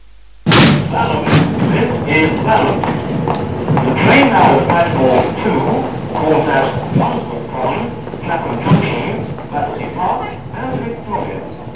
Balham pre-recorded platform announcement number 151 (year:1989)